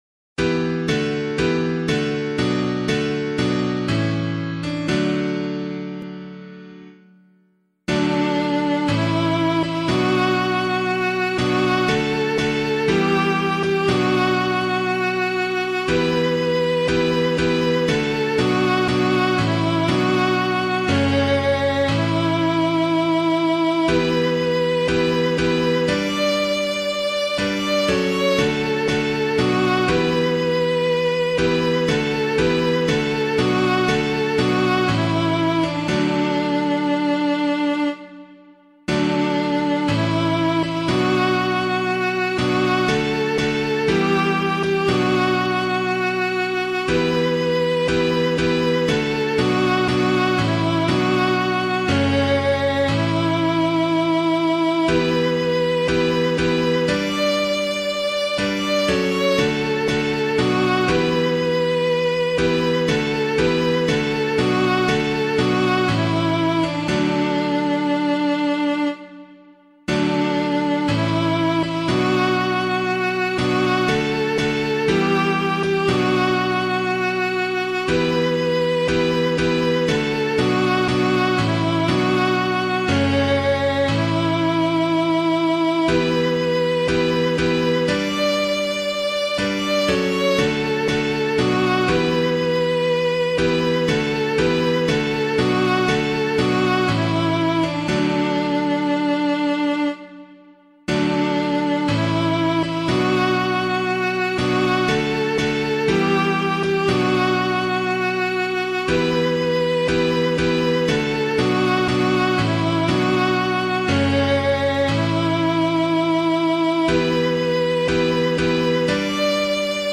Draw Nigh and Take the Body of the Lord [Neale - GUSTATE] - piano.mp3